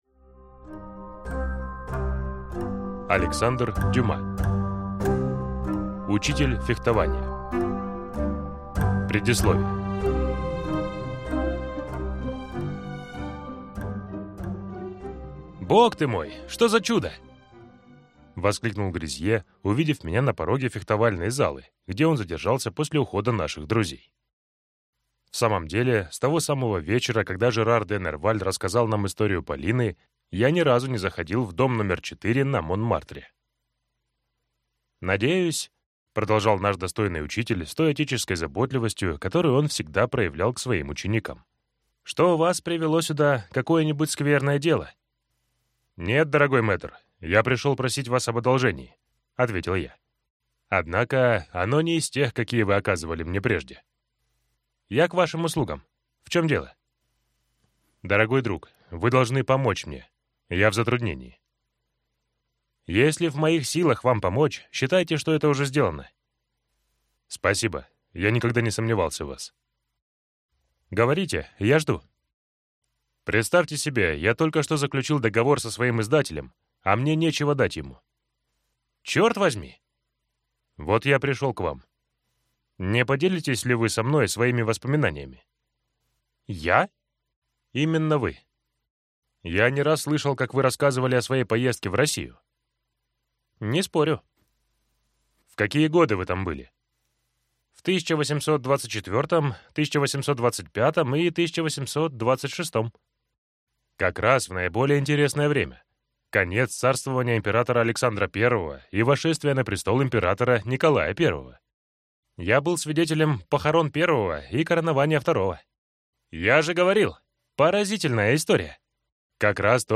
Аудиокнига Учитель фехтования | Библиотека аудиокниг